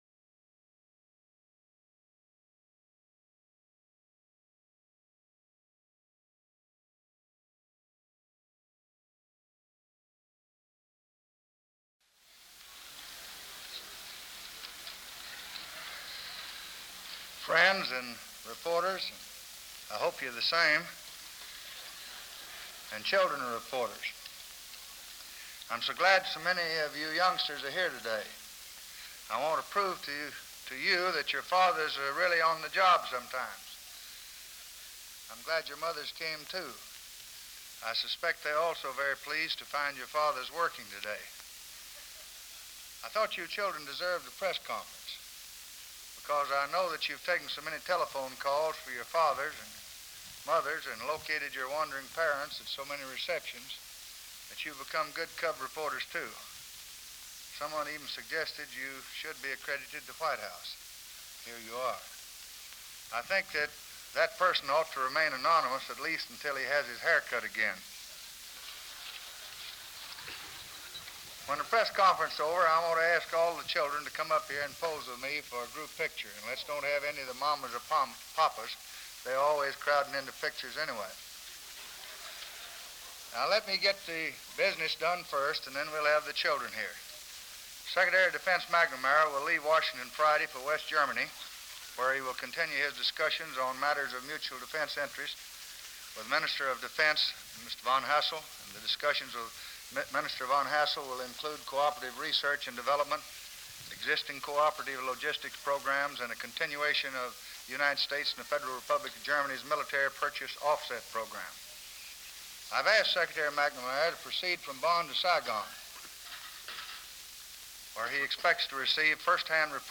May 6, 1964: Press Conference on the South Lawn | Miller Center